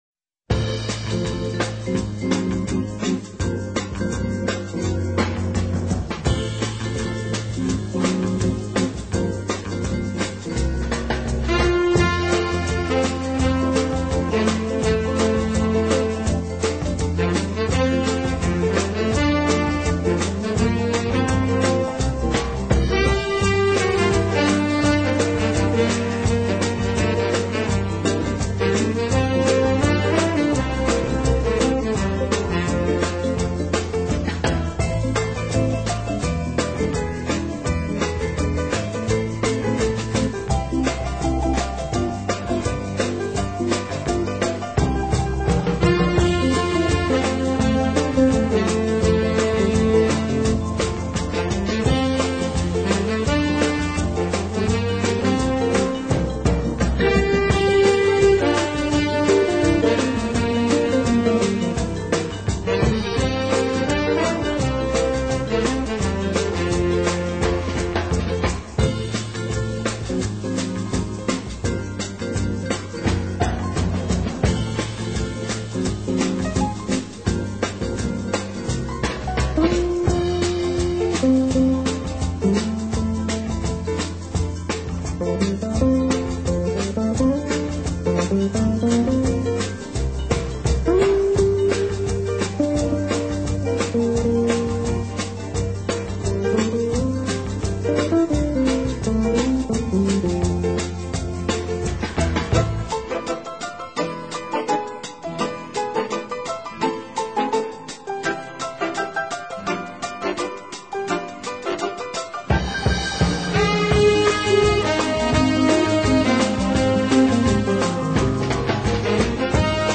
【顶级轻音乐】
曲曲优美动听的乐曲， 精致的配器、悦耳的和弦、优美的伴唱合声，加之种种
裁改编而成，整体感觉轻松惬意和稍显慵懒。